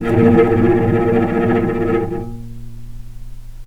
vc_trm-A#2-pp.aif